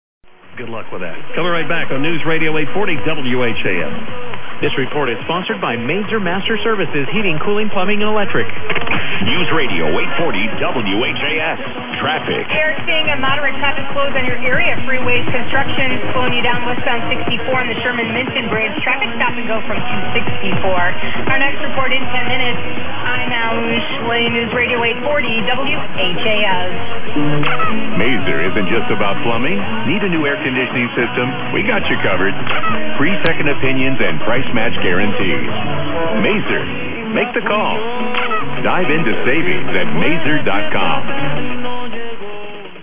SDRPlay (RSP1) w SDRUno + 450m / 1475ft Beverage @ 55 degrees (logs shown in yellow)
Both SDR recordings were unattended and scheduled to run between 13:30 and 17:30 EDT.
Funny to hear on some of the recordings, a live coverage of the eclipse.